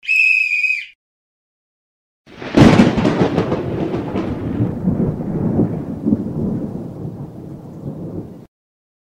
whistle.mp3